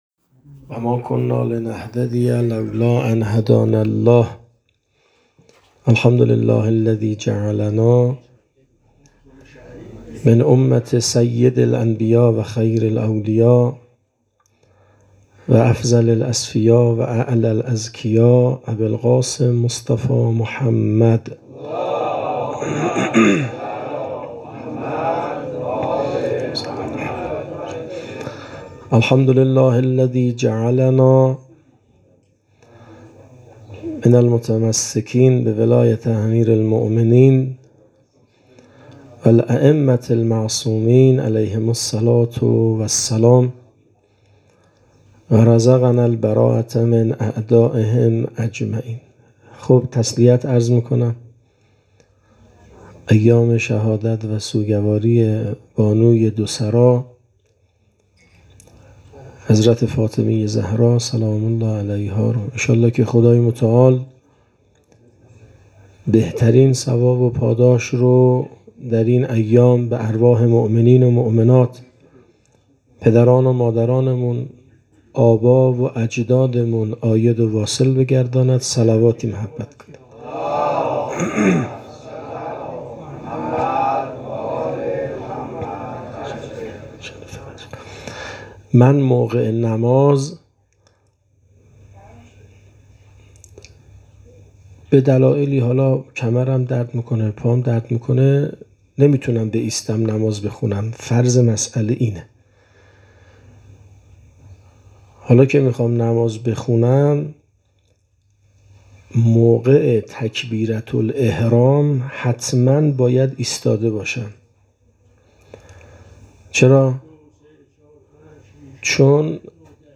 مراسم عزاداری شهادت صدیقه کبری حضرت فاطمه زهرا سلام الله